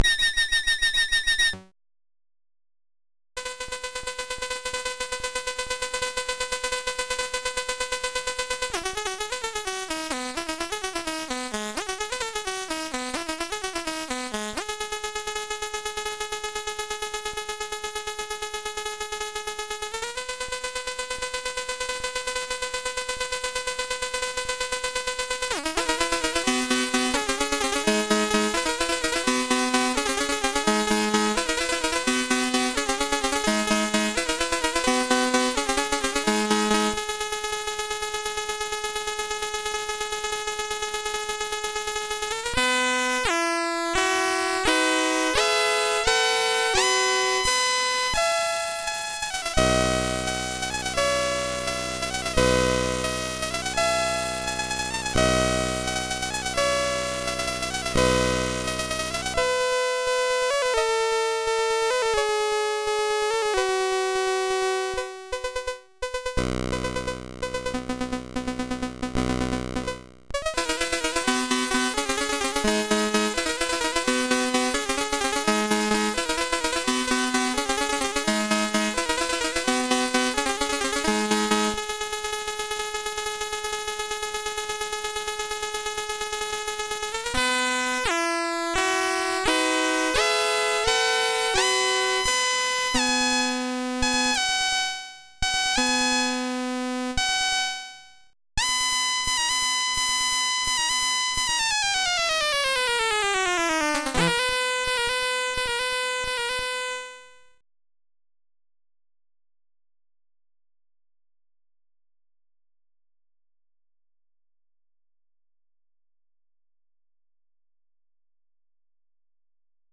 Commodore SID Music File
1 channel